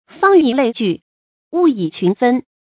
fāng yǐ lèi jù, wù yǐ qún fēn
方以类聚，物以群分发音